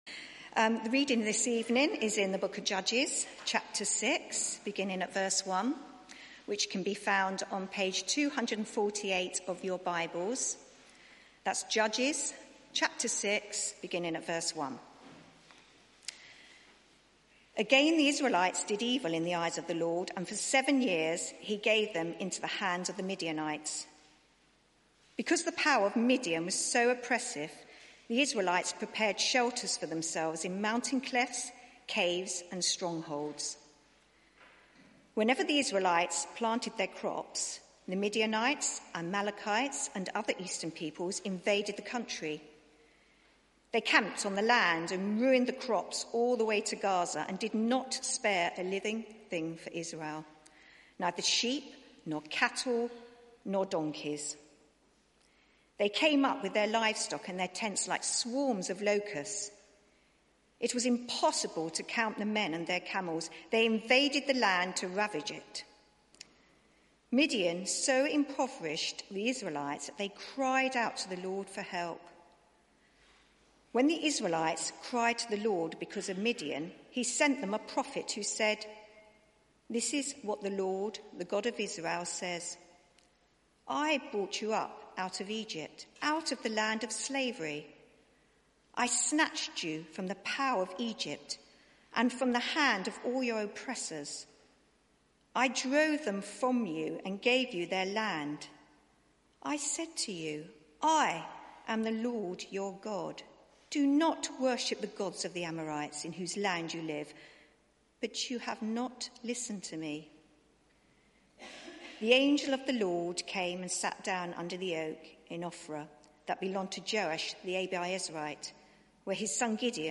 Media for 6:30pm Service on Sun 27th Oct 2019 18:30 Speaker
Passage: Judges 6 Series: Flawed Heroes in a Dark World Theme: Gideon: the Weak Mighty Warrior Sermon